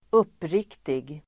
Uttal: [²'up:rik:tig]